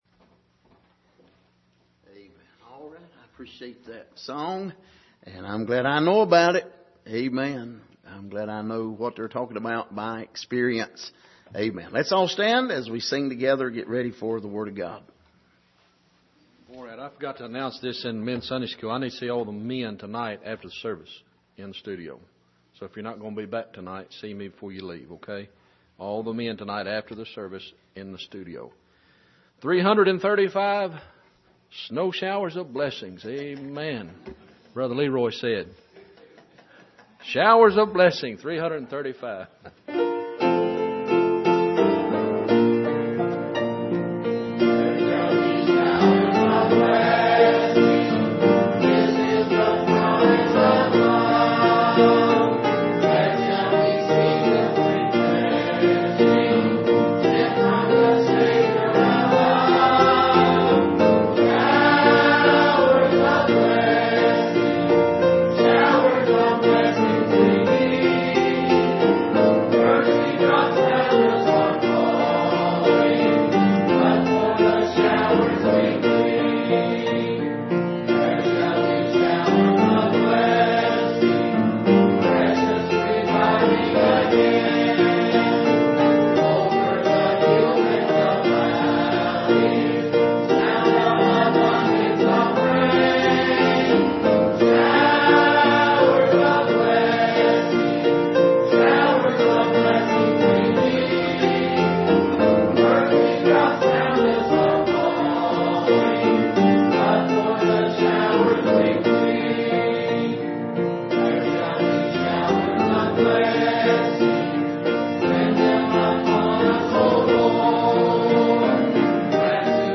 Passage: 1 John 5:10-13 Service: Sunday Morning